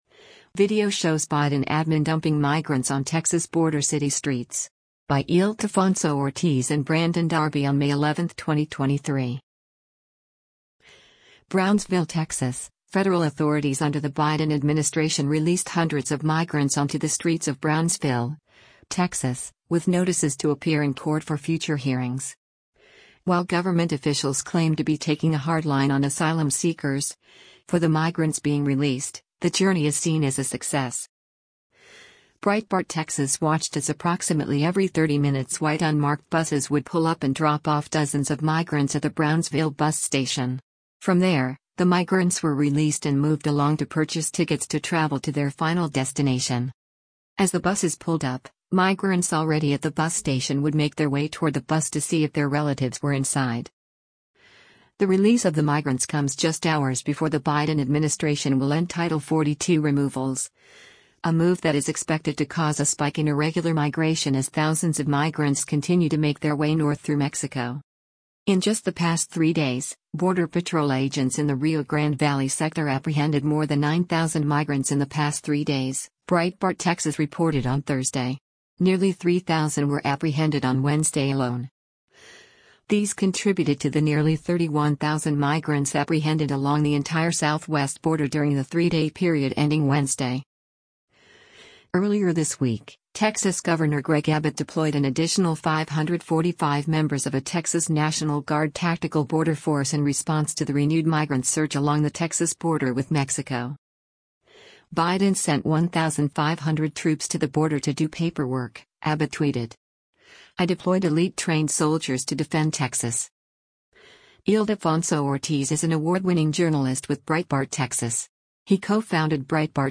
Video Shows Biden Admin Dumping Migrants on Texas Border City Streets
Breitbart Texas watched as approximately every 30 minutes white unmarked buses would pull up and drop off dozens of migrants at the Brownsville bus station.